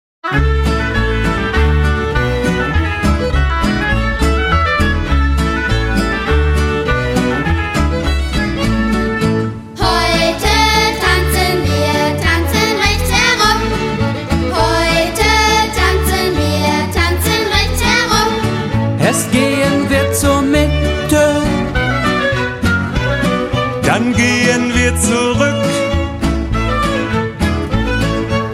frisch, fröhlich, folkloristisch!